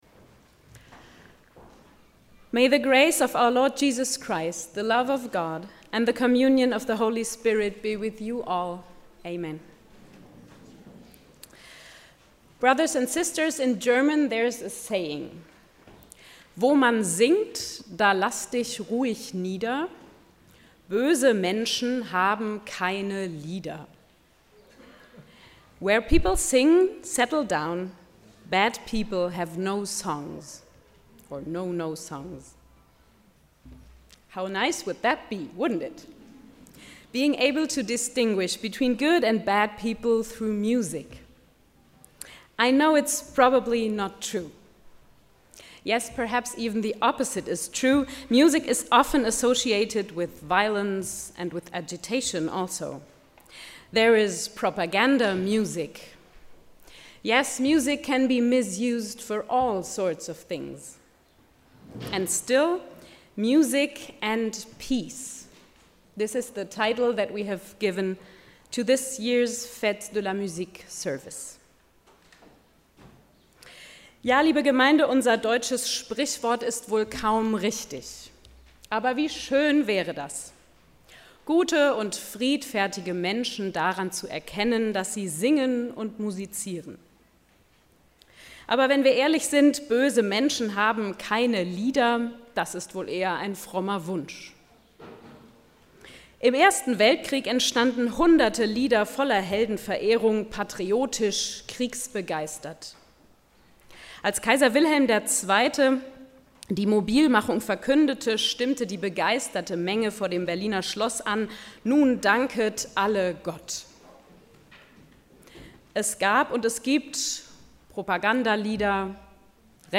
Sermon on joint ‘Fête de la Musique’ worship.